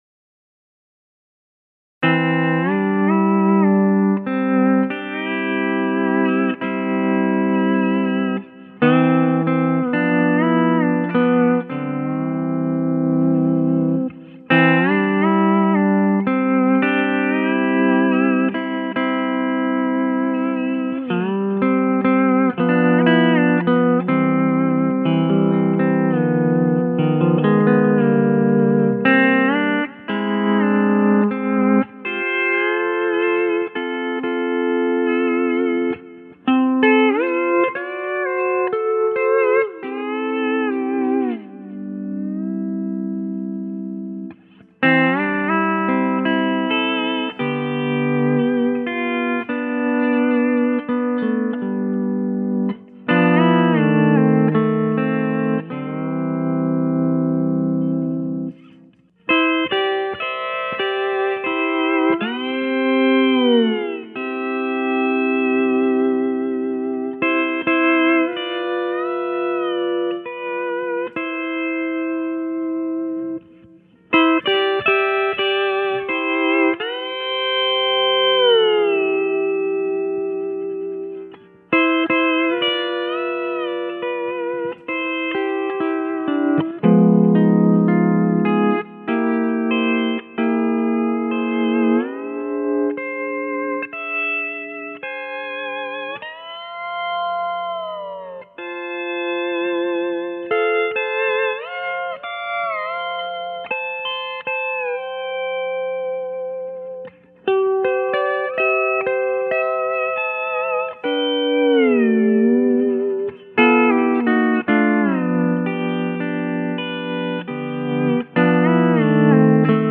* is voiced perfectly for the cleanest, purest steel guitar sound I have ever heard
I just turned it on, stuck a mic in front of it and recorded a few soundbytes -- here are some examples